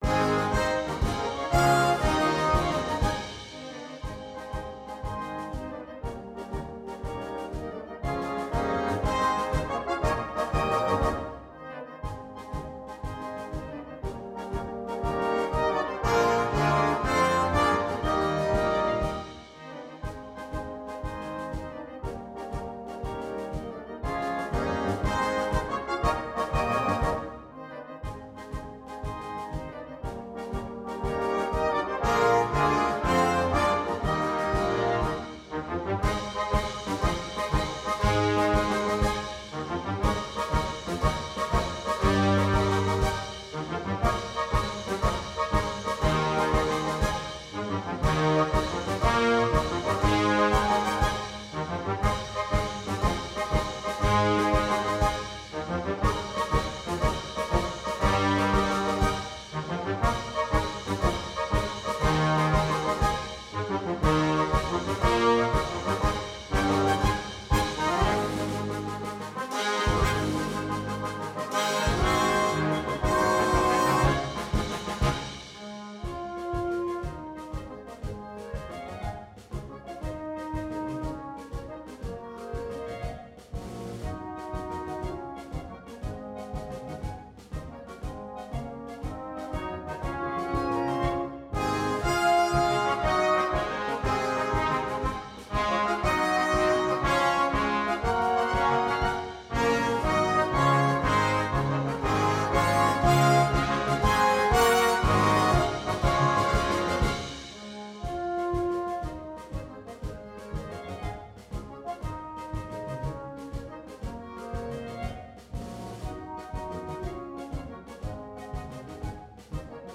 エゲルランダー様式バンド
フルバンド
ソロ楽器なし
行進の音楽